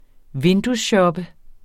Udtale [ ˈvendusˌɕʌbə ]